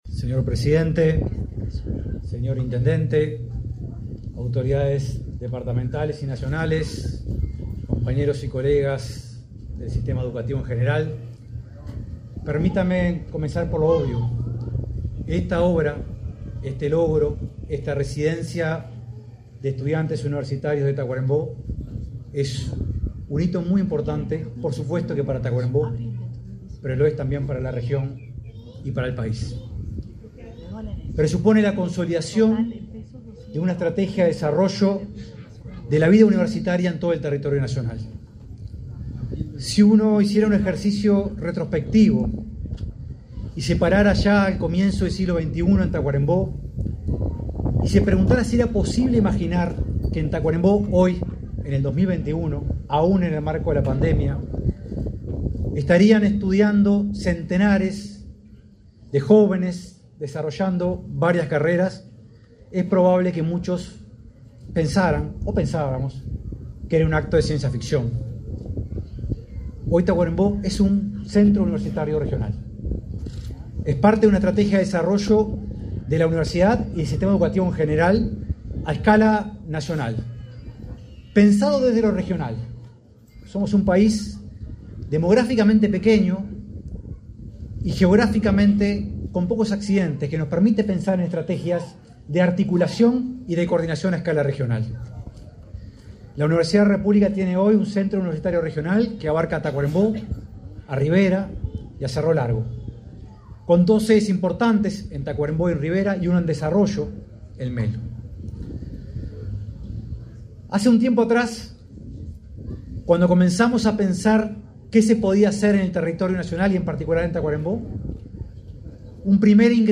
Conferencia de prensa por la inauguración de Residencia Universitaria en Tacuarembó
Con la presencia del presidente Luis Lacalle Pou, este 29 de noviembre, fue inaugurada la Residencia Universitaria en Tacuarembó.